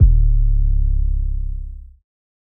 London808 w Slide.wav